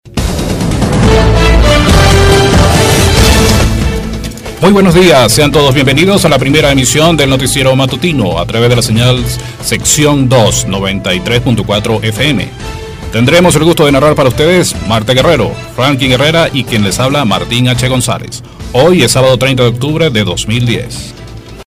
spanisch Südamerika
Sprechprobe: Werbung (Muttersprache):
Venezuelan announcer with manly voice, tones down, serious and hot.